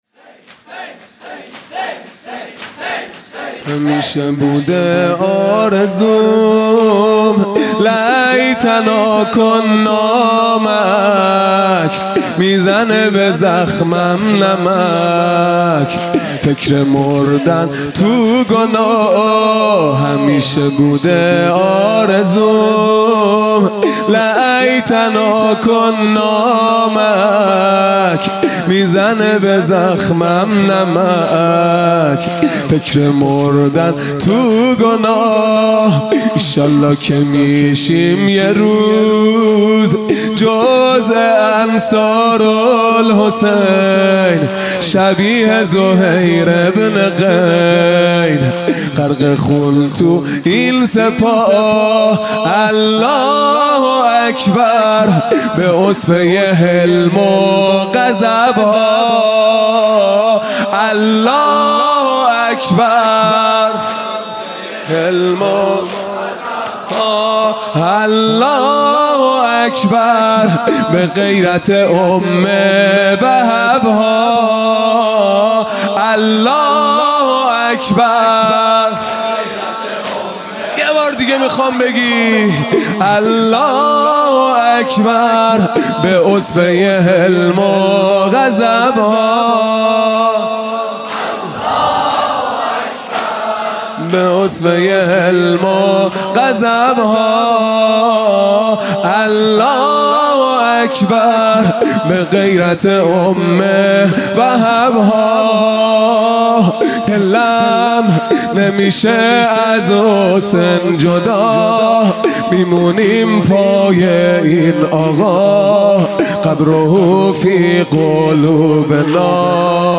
خیمه گاه - هیئت بچه های فاطمه (س) - زمینه | همیشه بوده آرزوم
محرم ۱۴۴۱ | شب چهارم